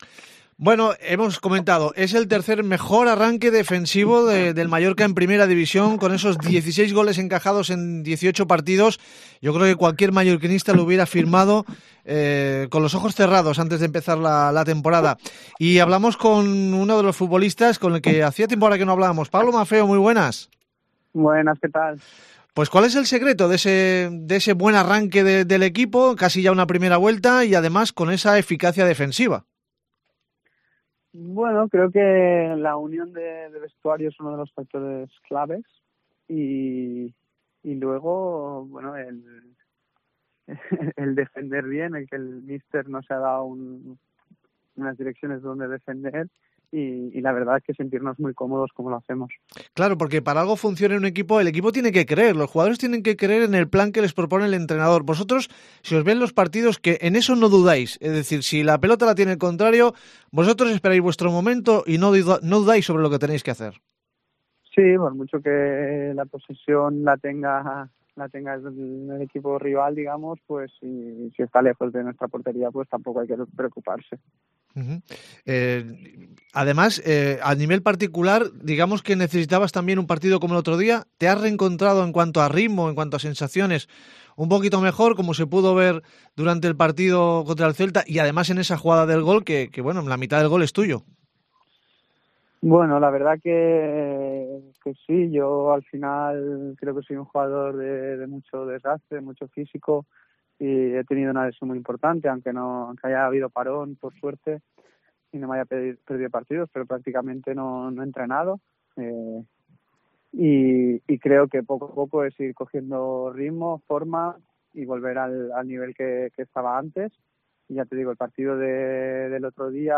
Una entrevista muy sincera la que ha concedido Pablo Maffeo a Deportes Cope Baleares. Admite que lo ha pasado muy mal por la lesión y situaciones personales delicadas, en la que ha encontrado el apoyo de un psicólogo y de compañeros como Dani y Ángel.